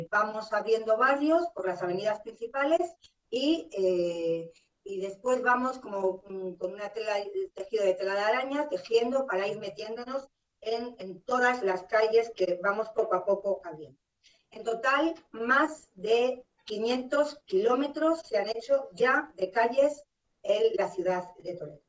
La alcaldesa de Toledo, Milagros Tolón, ha comparecido este martes para dar a conocer las últimas informaciones de las que dispone sobre el dispositivo que trabaja para paliar los efectos de la borrasca Filomena a su paso por la ciudad así como el estado de los diferentes servicios e infraestructuras municipales.